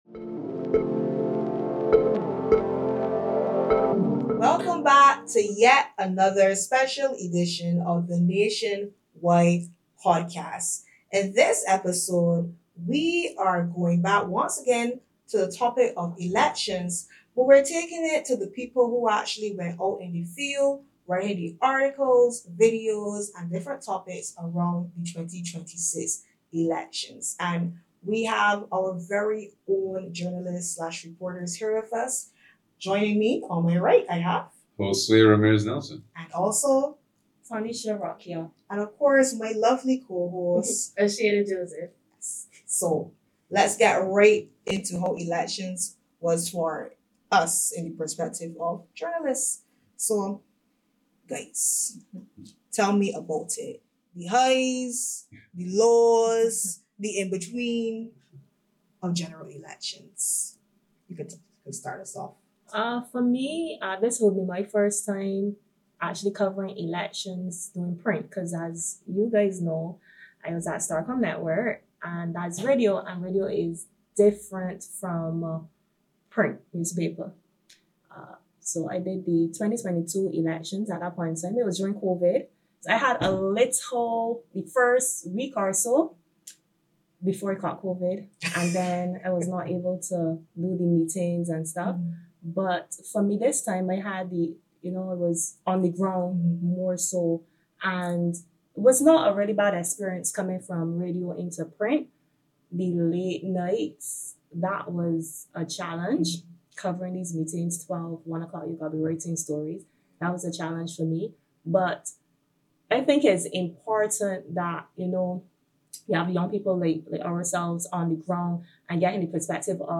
Nation Y Special Edition: At the 'Fourfront'- Four Journalists Share Their Perspective